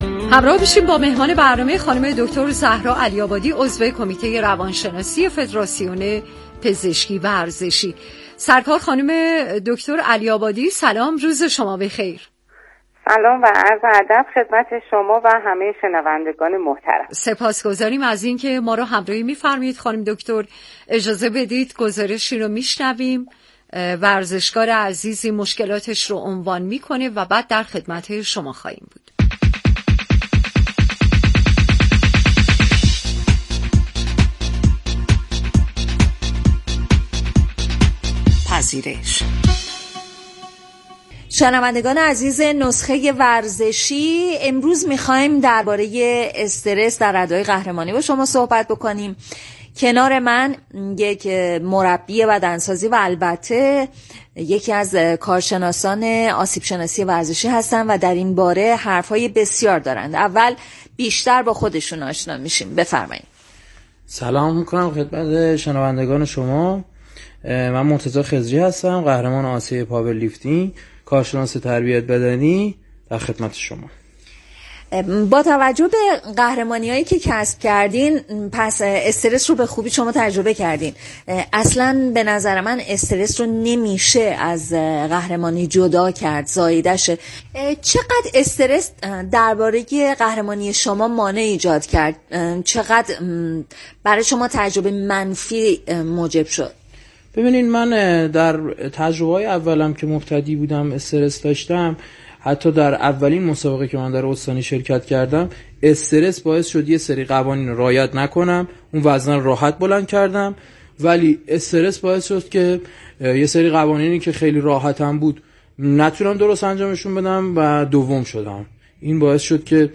/مصاحبه/